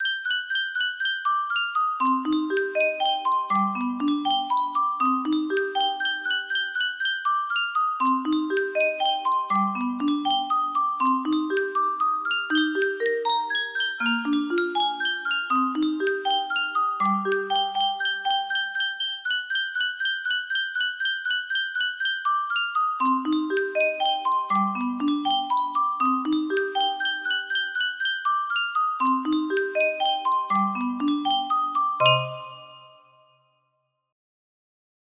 fur music box melody
Full range 60